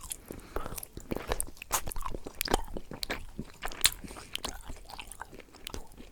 action_eat_1.ogg